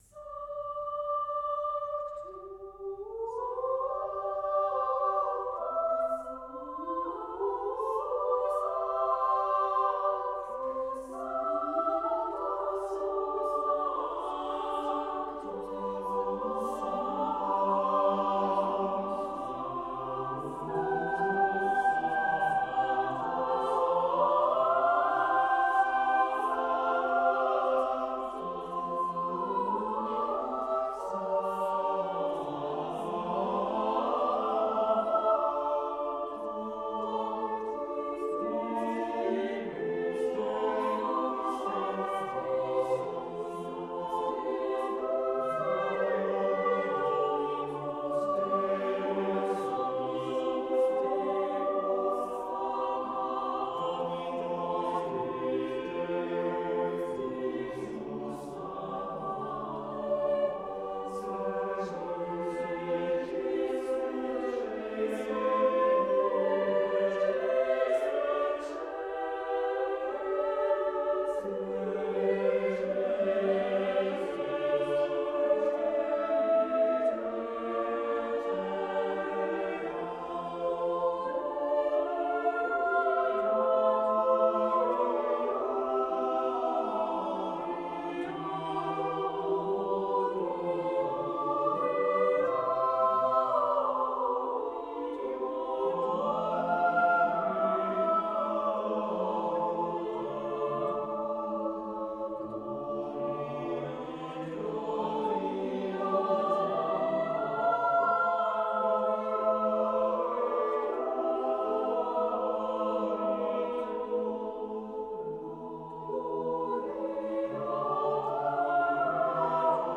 accomplished chamber choir